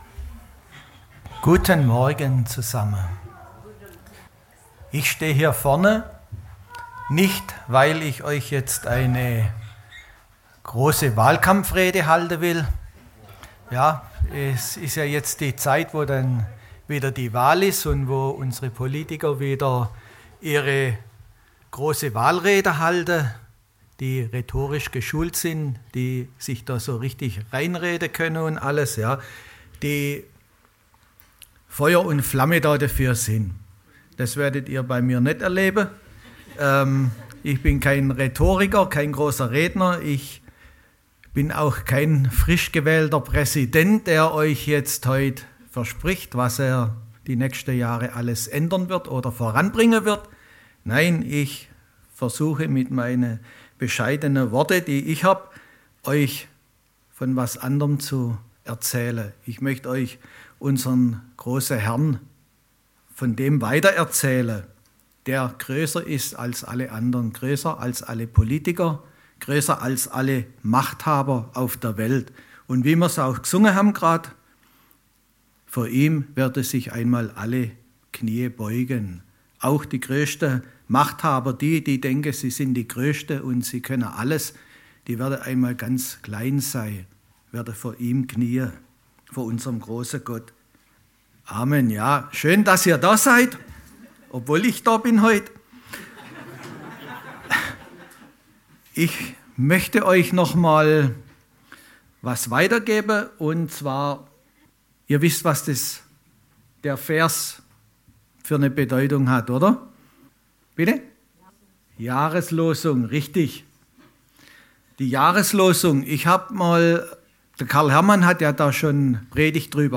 Predigt vom 26. Januar 2025 – Süddeutsche Gemeinschaft Künzelsau